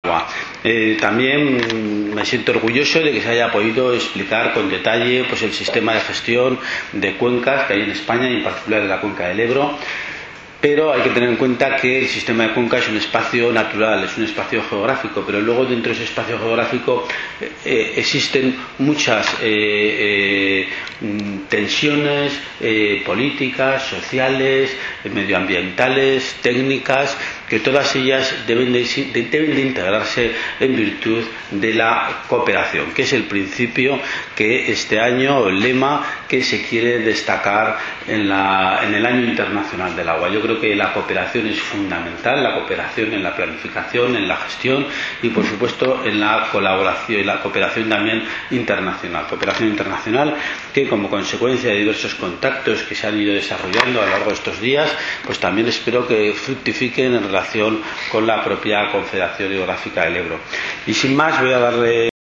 Documentos presidente_CHE_en_clausura_Conferencia_ONU.mp3